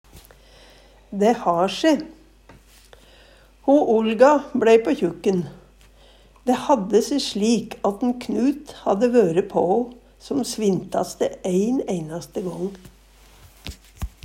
Dæ har se slik - Numedalsmål (en-US)